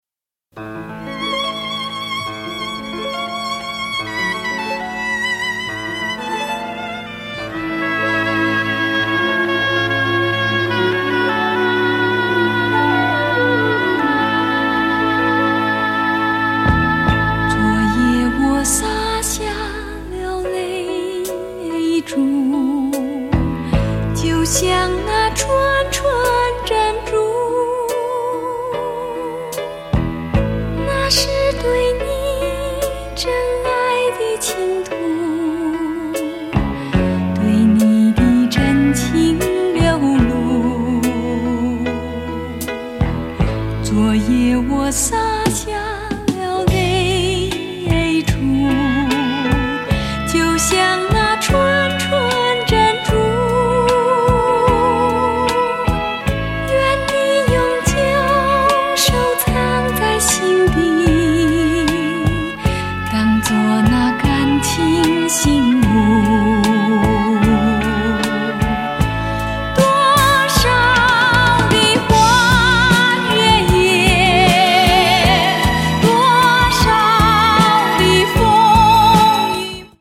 ★ 虛無縹緲的天籟嗓音，蘊涵夢幻般的極致柔美！
★ 細膩幽邃的優雅歌聲，瀰漫氤氳般浪漫的韻味！